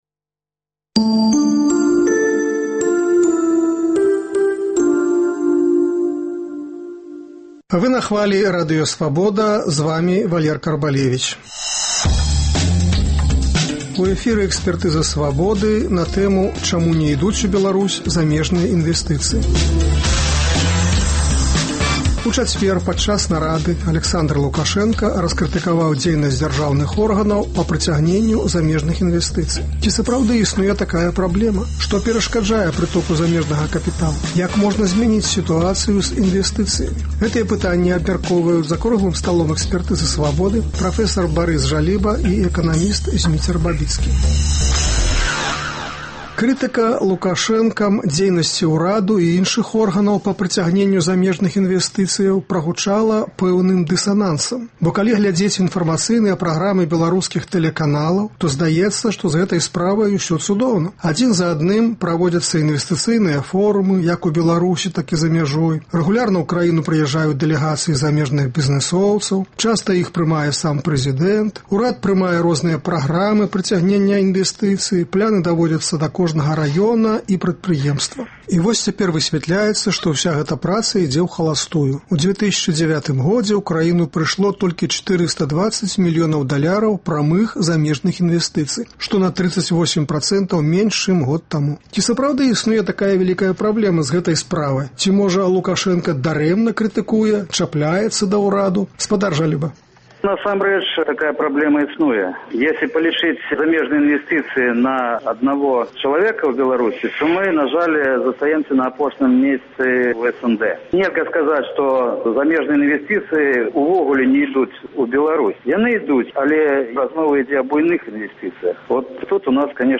за круглым сталом